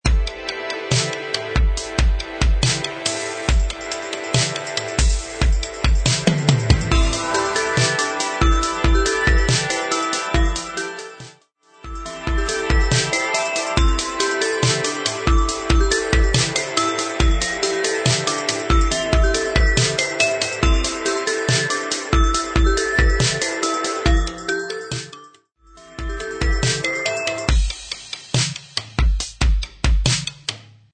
Music Beds
Smooth Electronic